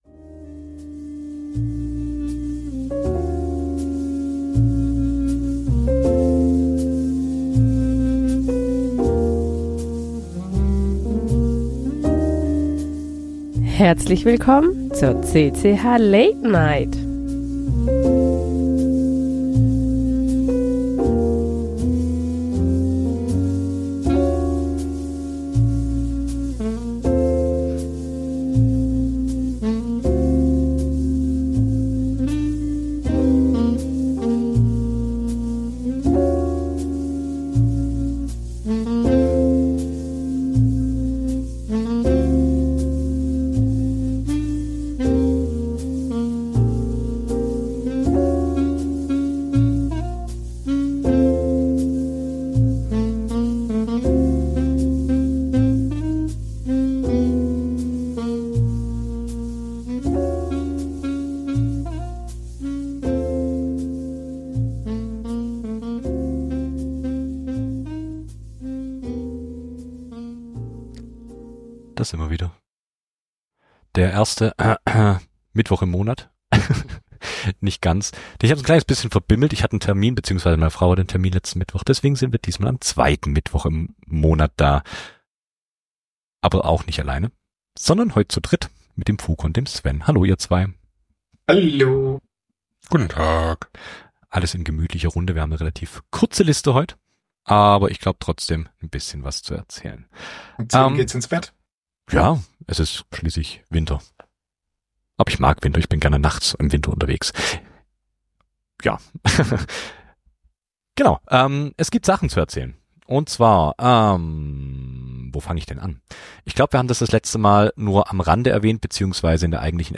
Die CCH! Late Night! ist eure monatliche Live-Keyboard-Late-Night-Show.